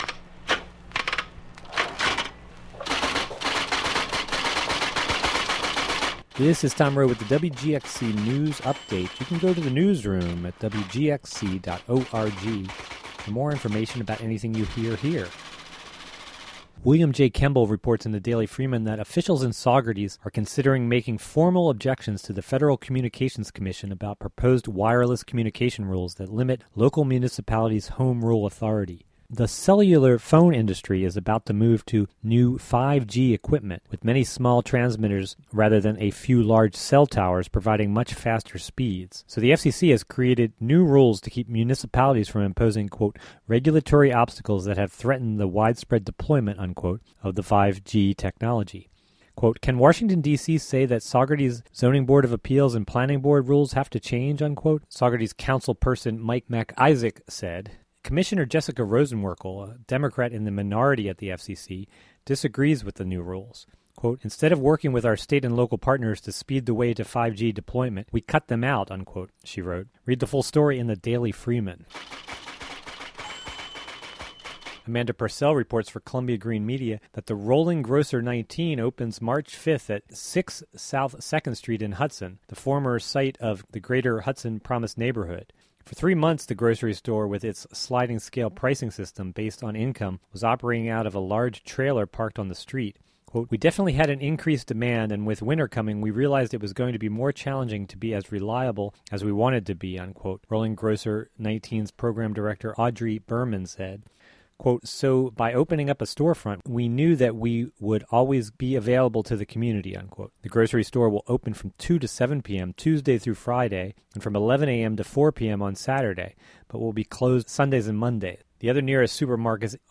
Uncategorized Local headlines and weather